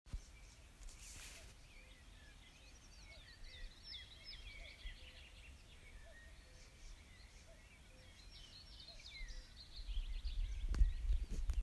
кукушка, Cuculus canorus